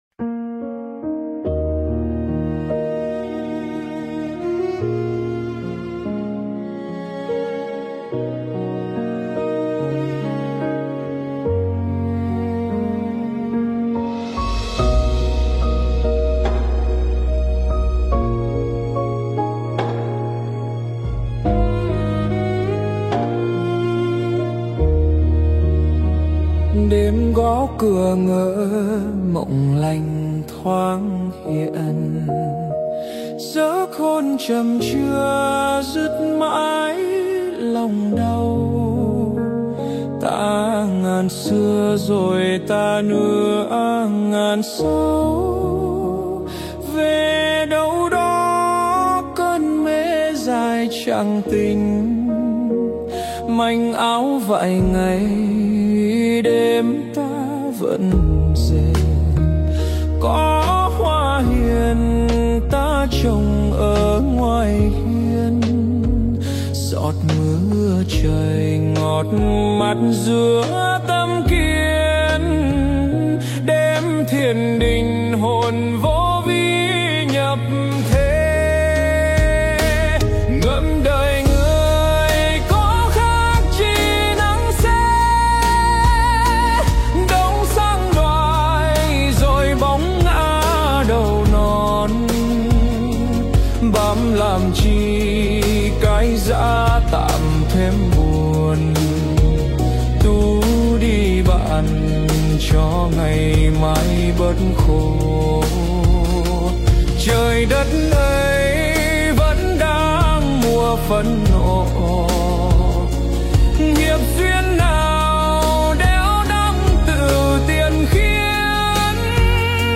Hát Kinh "Mộng Hoá Sinh" - Vị Lai Pháp